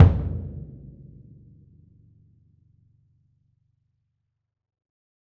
Percussion
BDrumNewhit_v6_rr2_Sum.wav